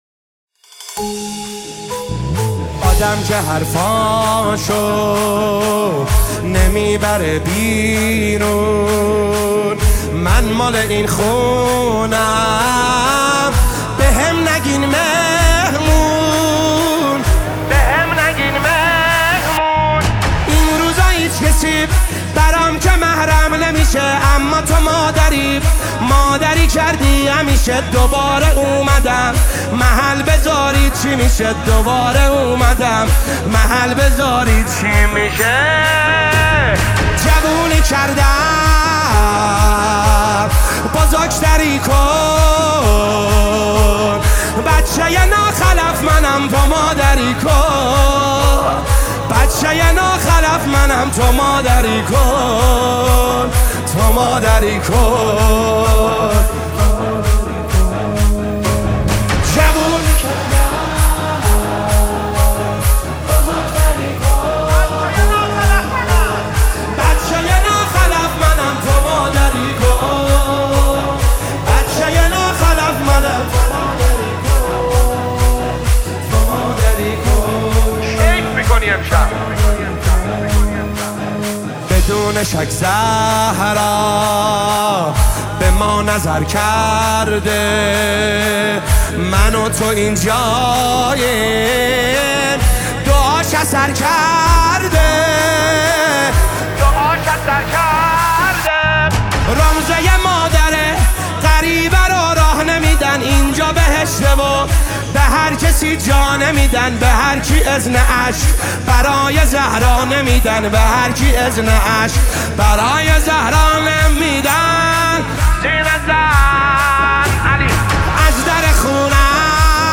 مداحی فاطميه